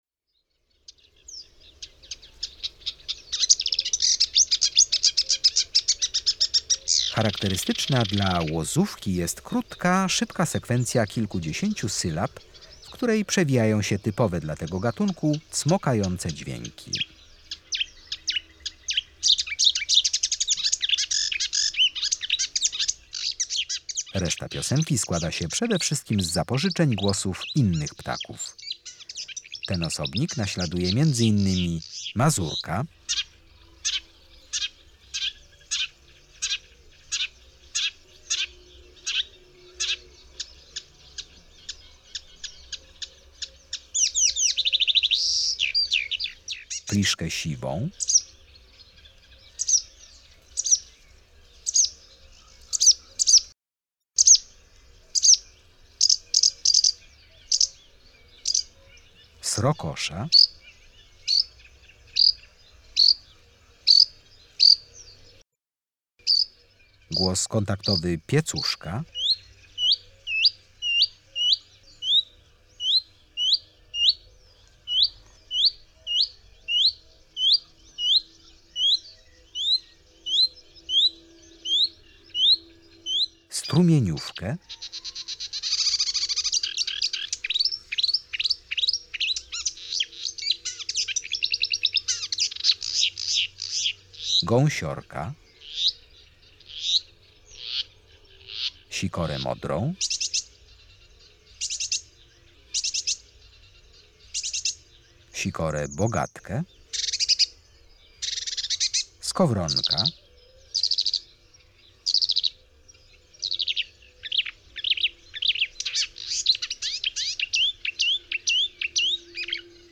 35-Lozowka.mp3